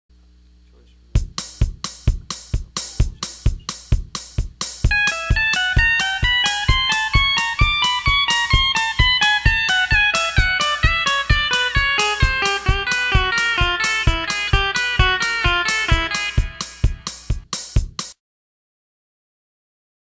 Fast No Echo Tab 2.wma